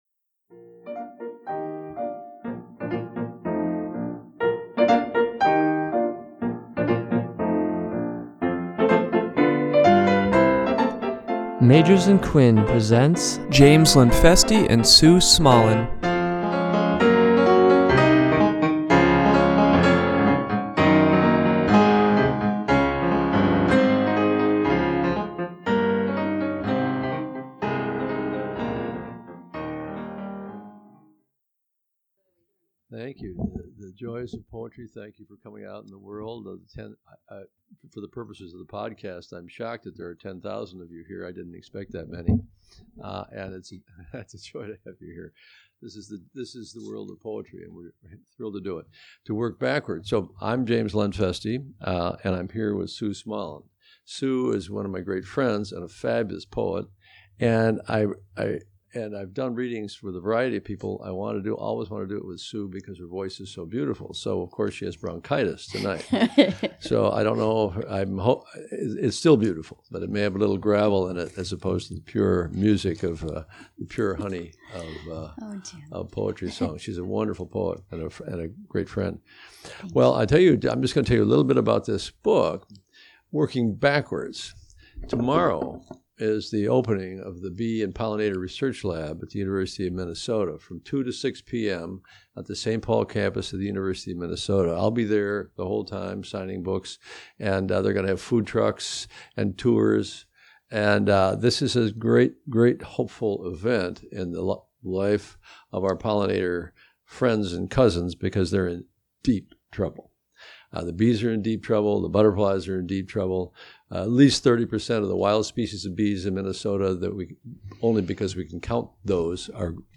Recorded at Magers and Quinn Booksellers on October 28, 2016.